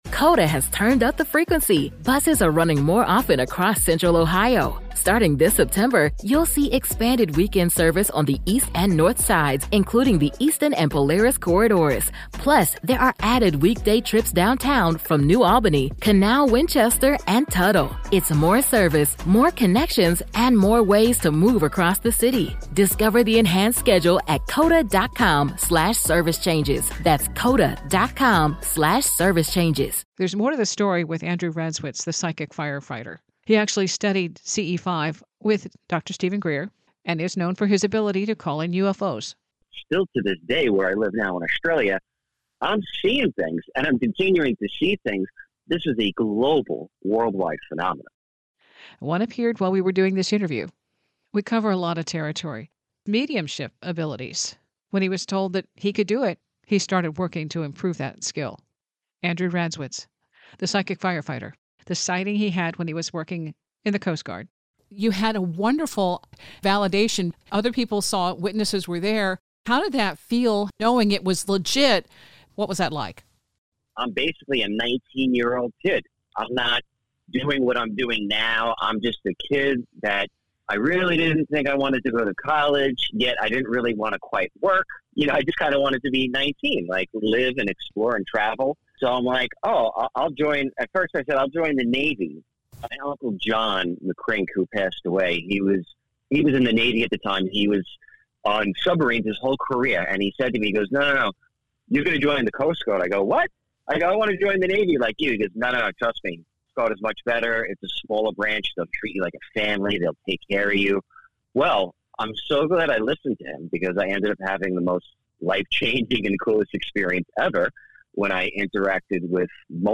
The cool thing - a UFO showed up and buzzed him as he talked with me during this interview.